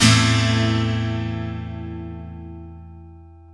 GTR EL-AC 0E.wav